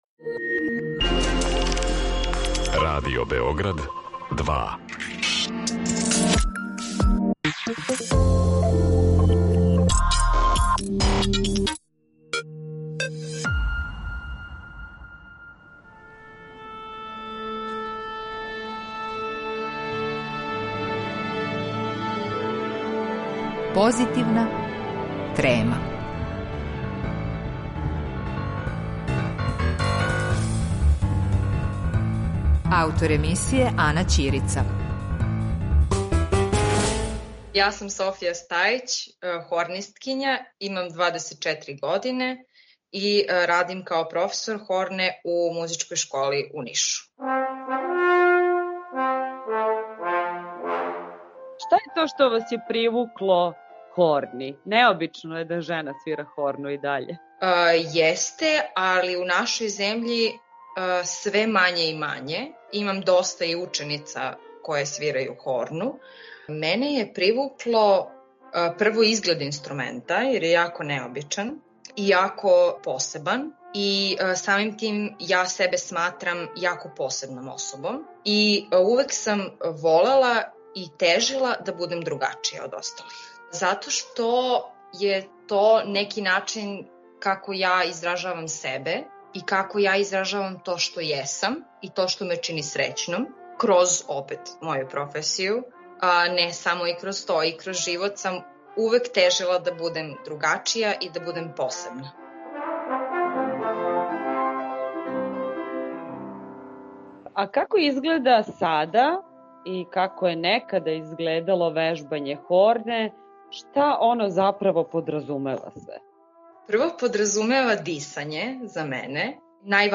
Хорнисткиња